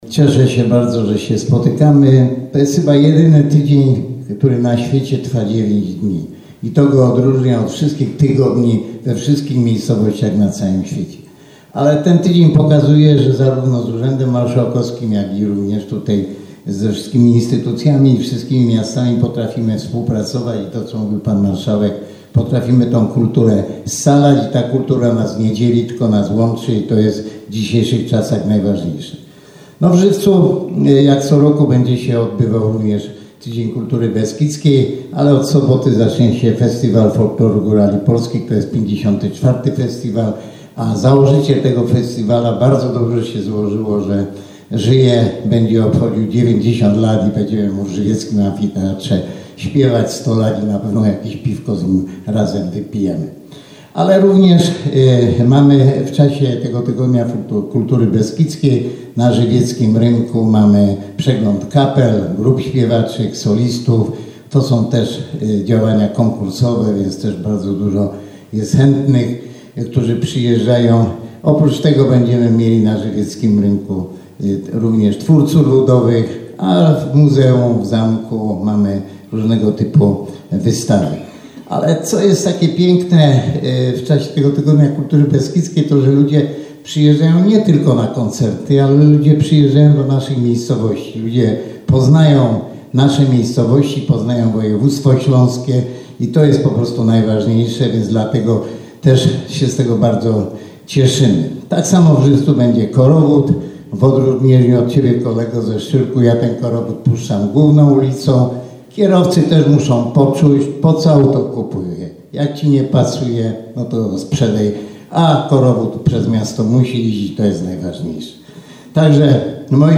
Reprezentanci Wisły, Szczyrku, Żywca, Oświęcimia, Makowa Podhalańskiego, Istebnej, Ujsół i Jabłonkowa na Zaolziu wystąpili dzisiaj podczas konferencji prasowej w Miejskiej Bibliotece Publicznej w Wiśle.